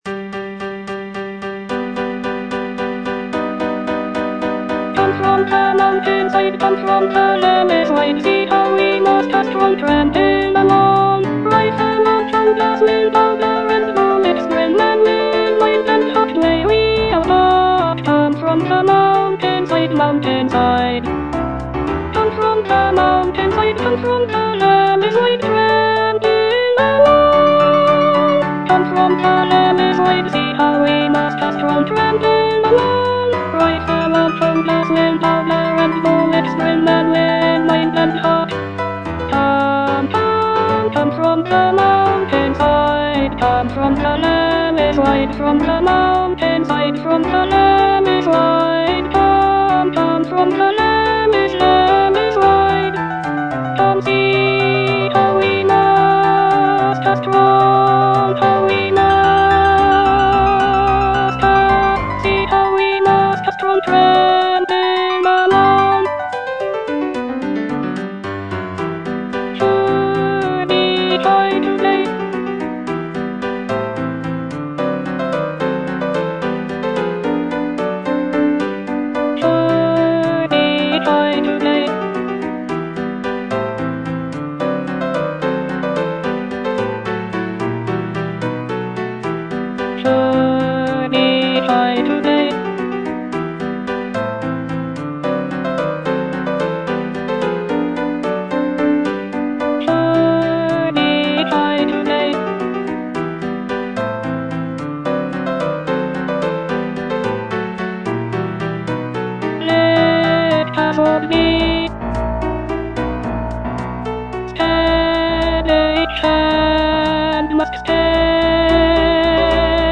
E. ELGAR - FROM THE BAVARIAN HIGHLANDS The marksmen (alto II) (Voice with metronome) Ads stop: auto-stop